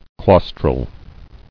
[claus·tral]